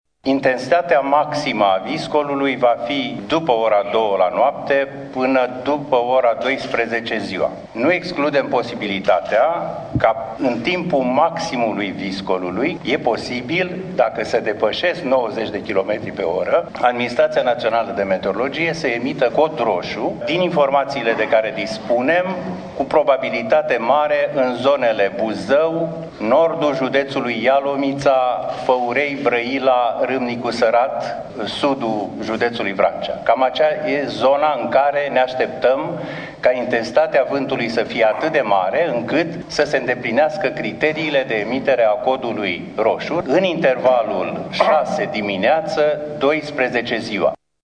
Directorul Administraţiei Naţionale de Meteorologie, Ion Sandu.